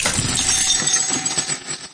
jackpot_coin_fly.mp3